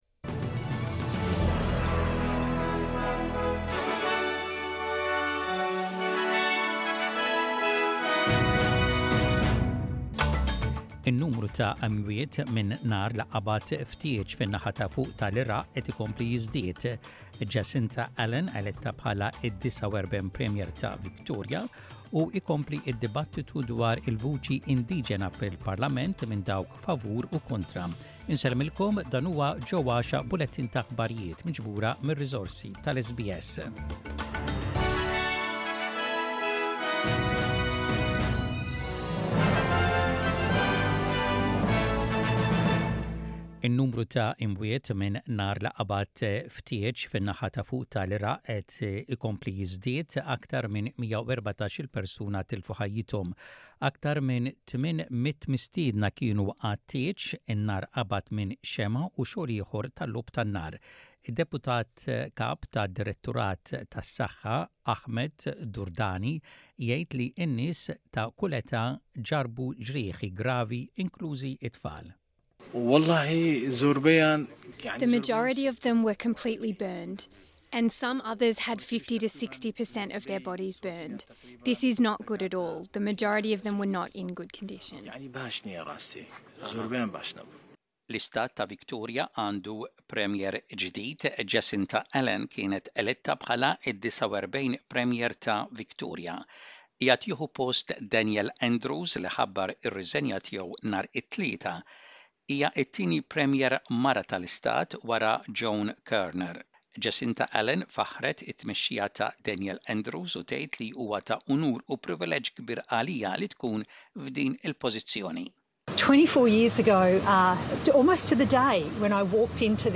SBS Radio | Maltese News: 29.09.23